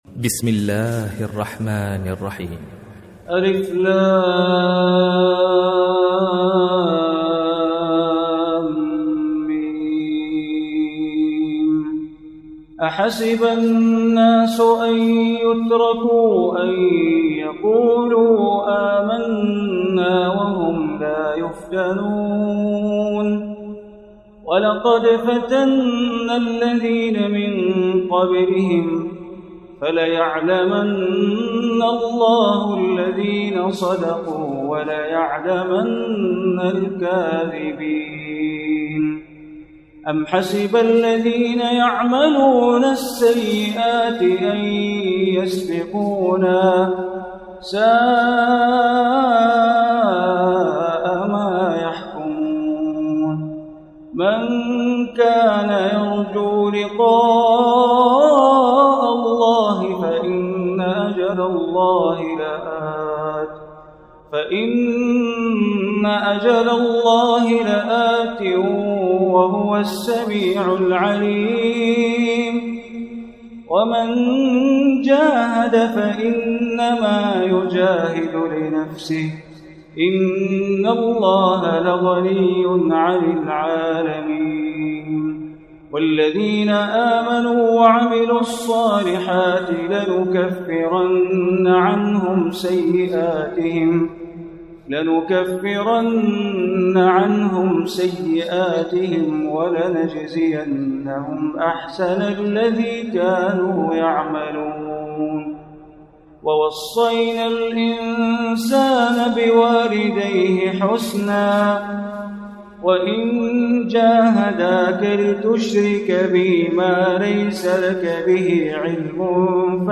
Surah Al-Ankabut Recitation by Bandar Baleela
Surah Al-Ankabut, is 29 surah of Holy Quran. Listen online or download audio recitation of Surah Al-Ankabut free in the voice of Sheikh Bandar Baleela.